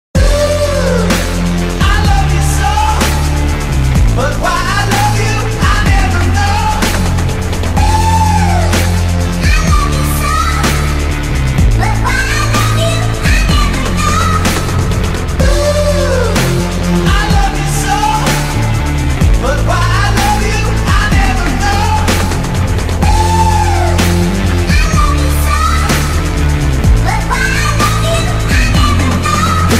• Качество: 128, Stereo
Хип-хоп